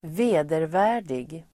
Uttal: [²v'e:dervä:r_dig]